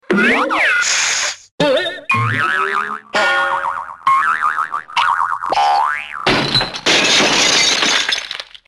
Бег Тома и Джерри, удары, крики и другие звуковые эффекты в mp3
14. Падения, удары, прыжки и разбивание
tom-and-jerry-udary-padenia-razbivanie.mp3